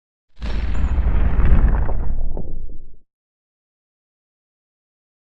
Explosion Low Fire Destruction Version 4 - Short Passing